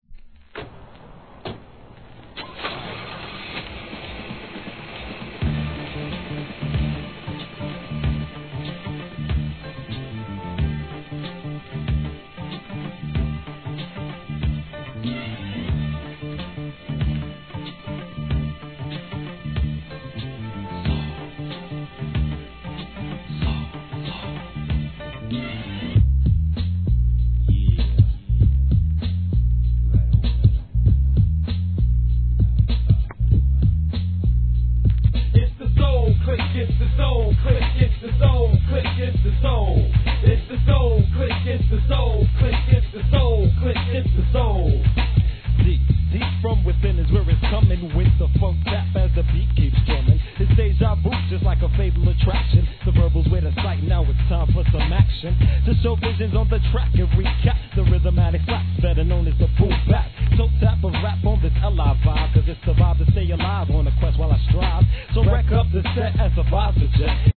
HIP HOP/R&B
怒渋なフォーン・サンプリング＆BEATに心を揺さぶられるはず!